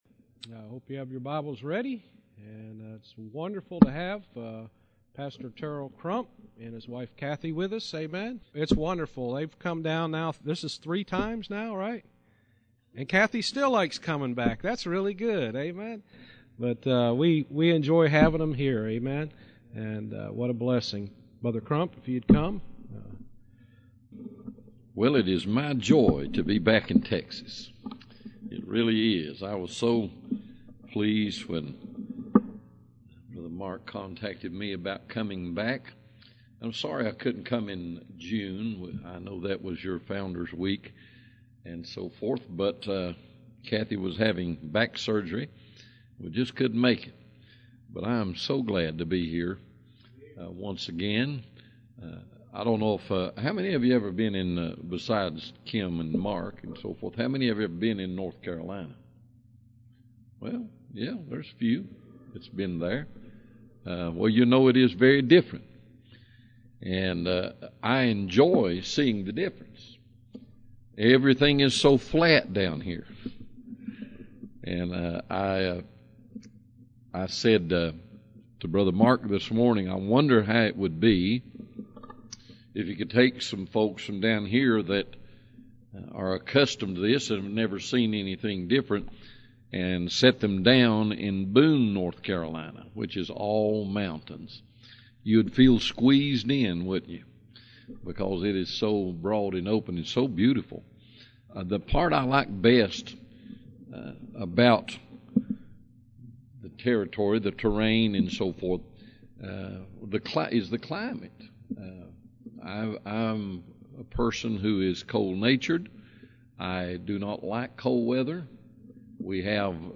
Fall Revival – Proof of Life